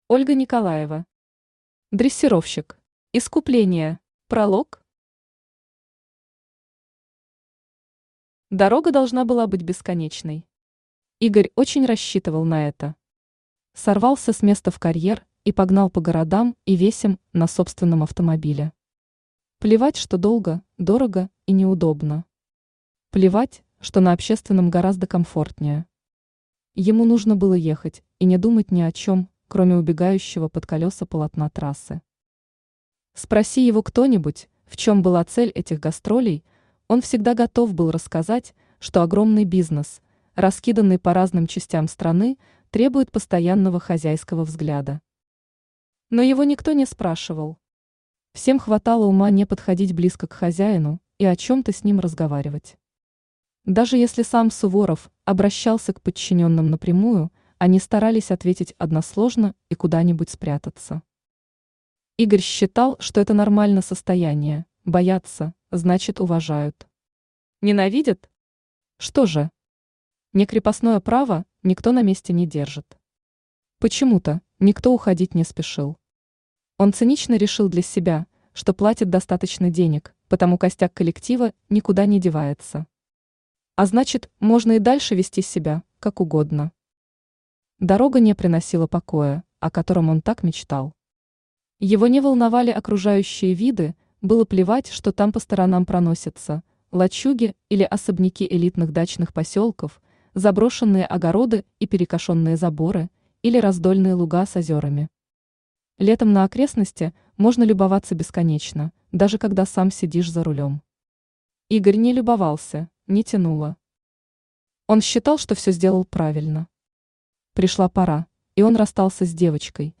Аудиокнига Дрессировщик. Искупление | Библиотека аудиокниг
Искупление Автор Ольга Николаева Читает аудиокнигу Авточтец ЛитРес.